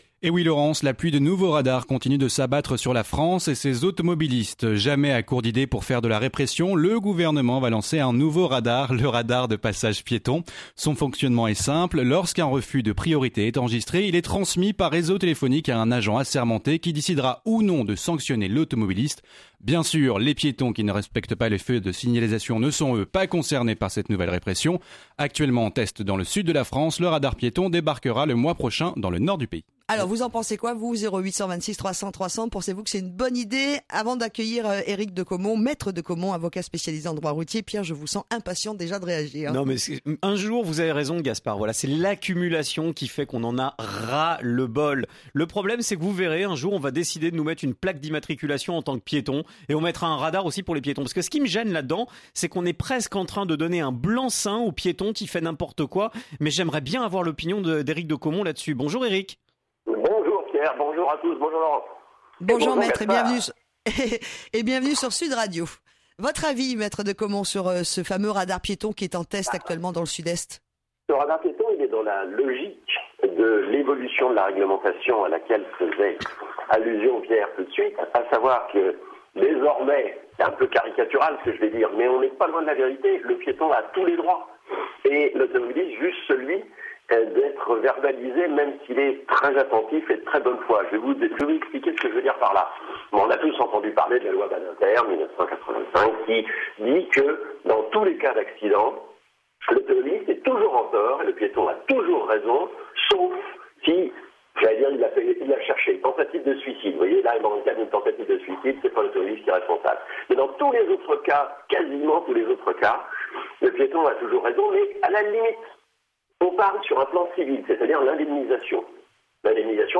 l’invité de l’émission « Ca Roule » sur Sud Radio